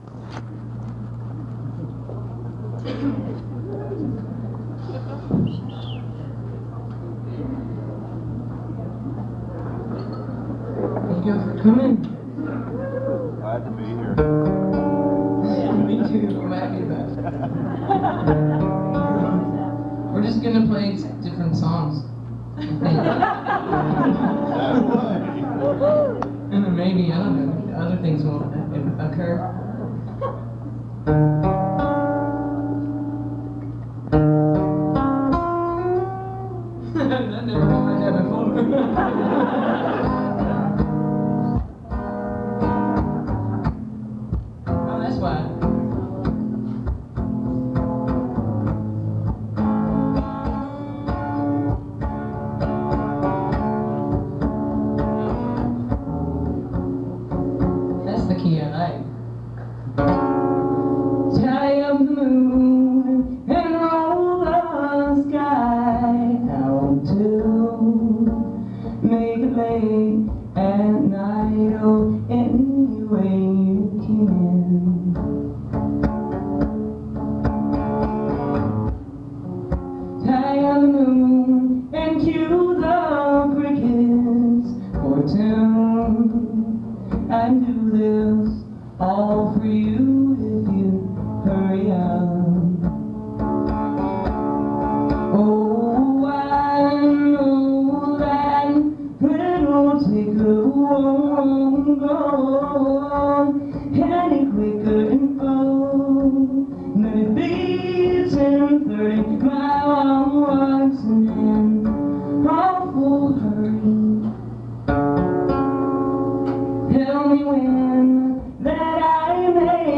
(songwriters in the round)
(first set)